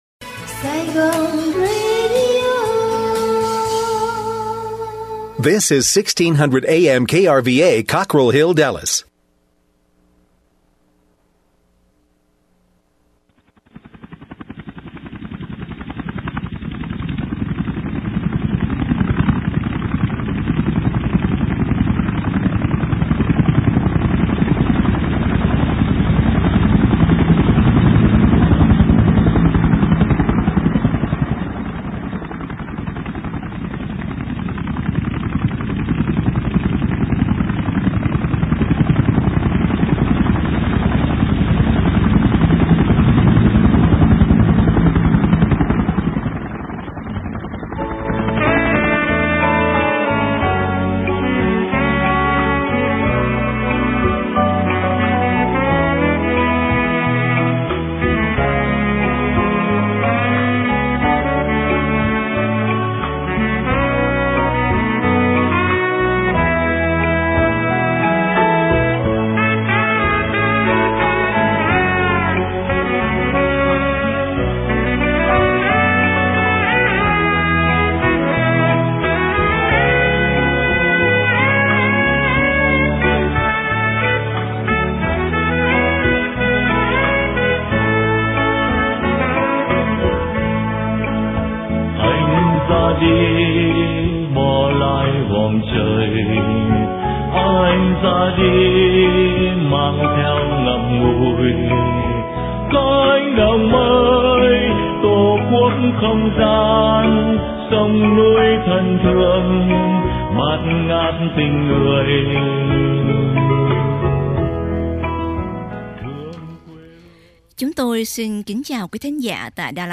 Từ Cánh Đồng Mây: Phỏng Vấn